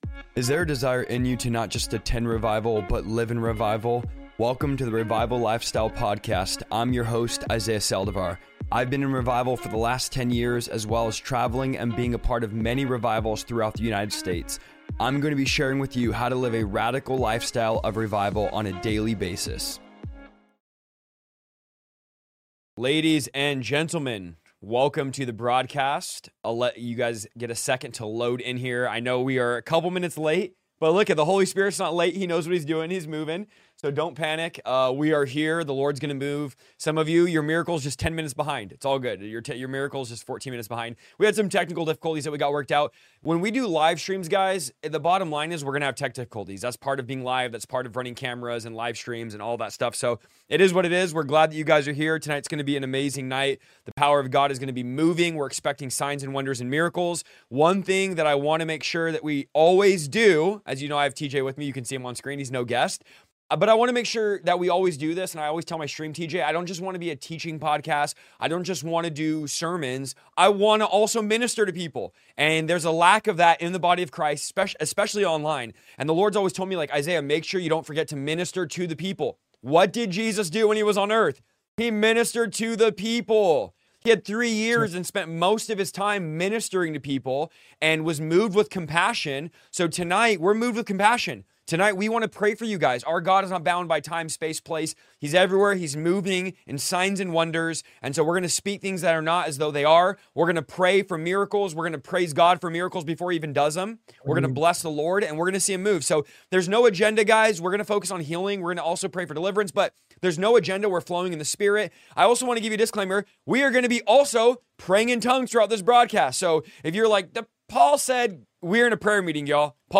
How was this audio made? Need a Miracle? Join Us for Live Healing Prayer!